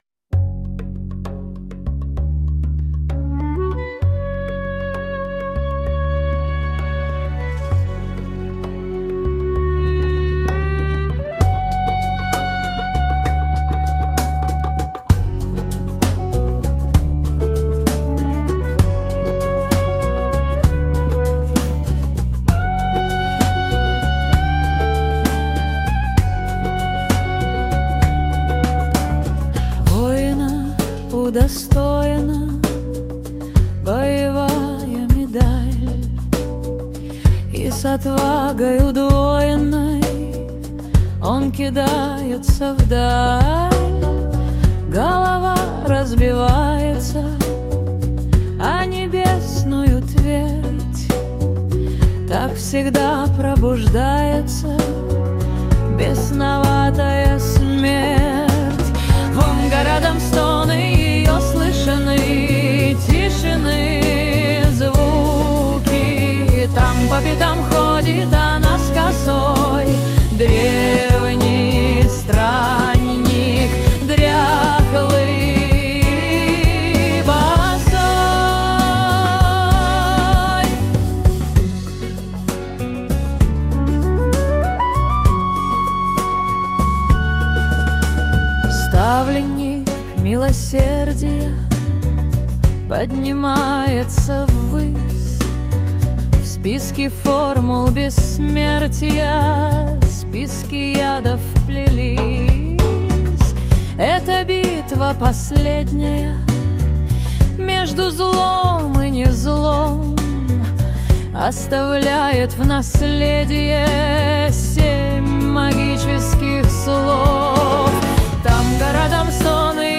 • Жанр: AI Generated